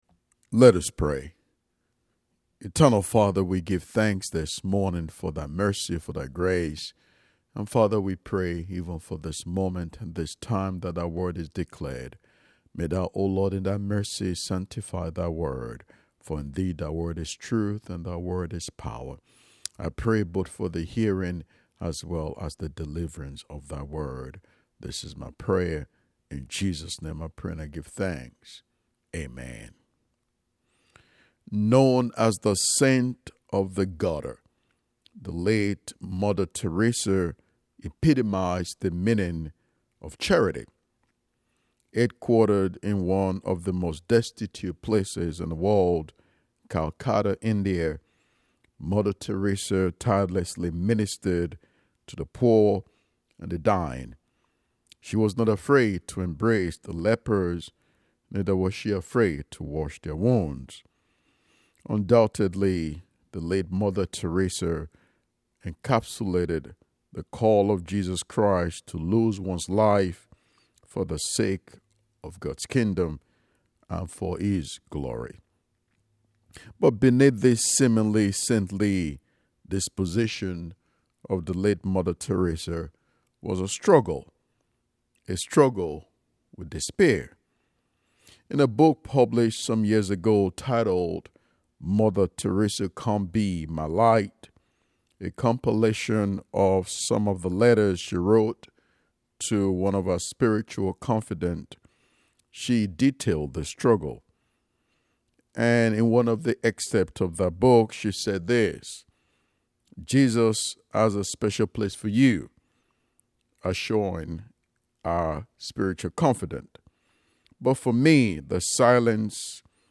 10:30 AM Service
10:30 AM Service NIP IT IN THE BUD Click to listen to the sermon. https